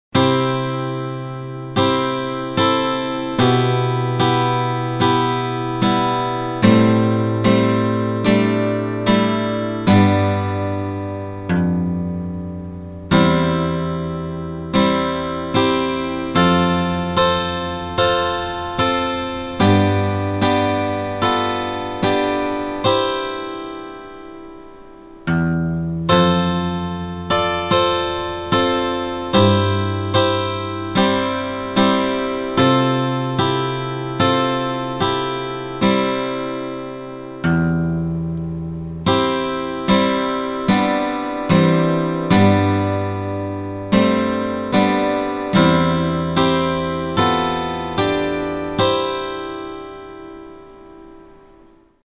この童謡をテンポを下げアコースティックギターでしっとりと静かな感じにしてみました。